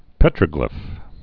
(pĕtrə-glĭf)